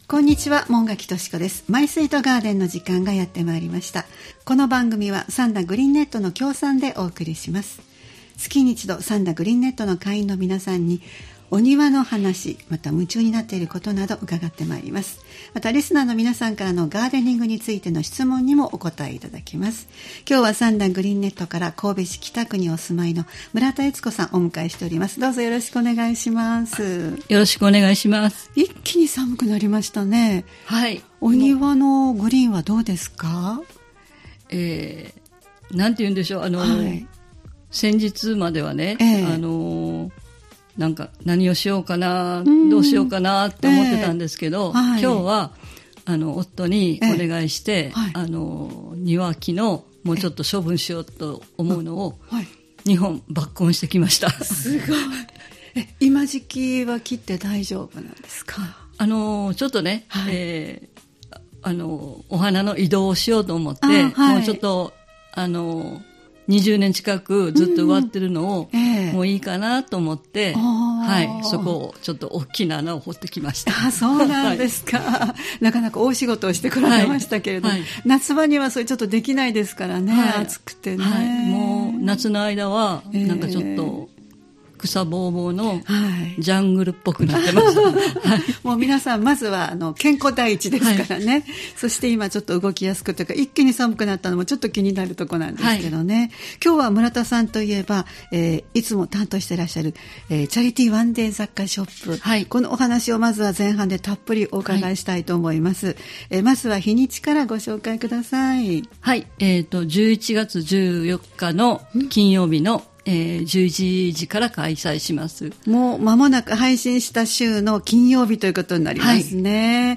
毎月第2火曜日は兵庫県三田市、神戸市北区、西宮市北部でオープンガーデンを開催されている三田グリーンネットの会員の方をスタジオにお迎えしてお庭の様子をお聞きする「マイスイートガーデン」（協賛：三田グリーンネット）をポッドキャスト配信しています（再生ボタン▶を押すと番組が始まります）